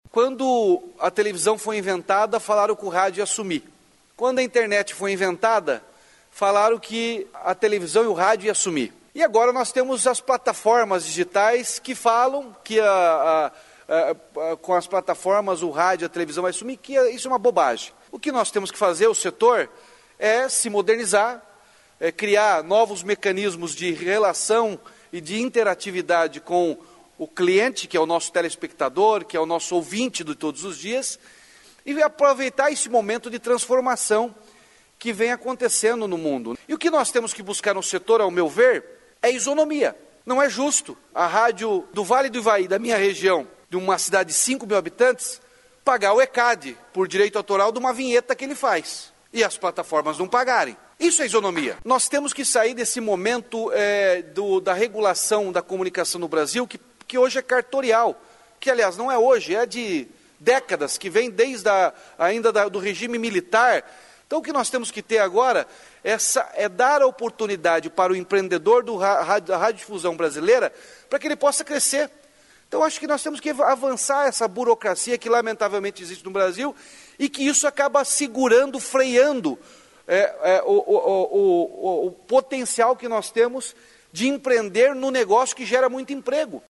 Em entrevista à Agência Estadual de Notícias, o governador defendeu a modernização da regulação da comunicação no Brasil e a isonomia entre rádios, TVs e big techs.